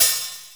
HH OPEN22.wav